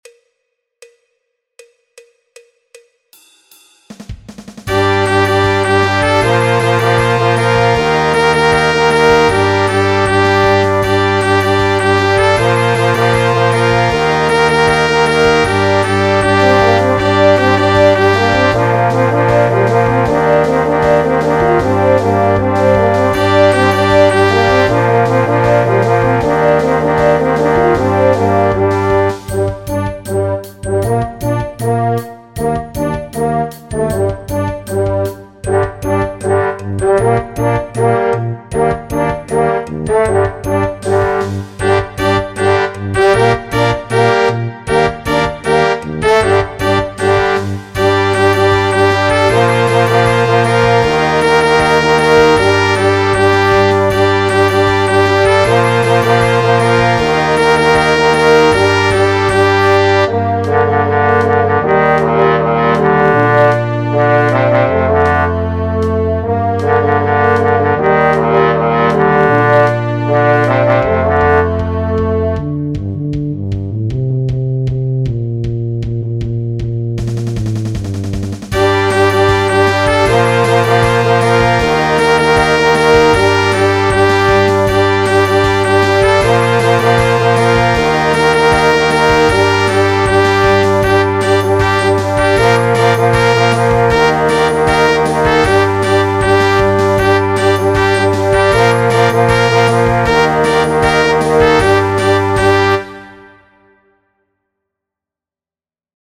Gesamtschwierigkeit: Einfach